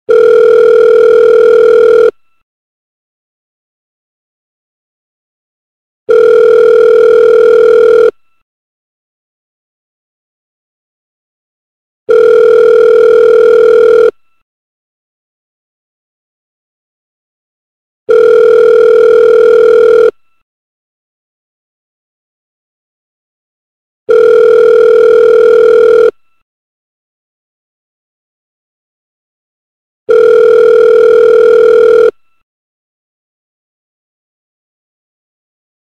north american ring tone – type a (louder)
north-american-ring-tone-type-a-louder.mp3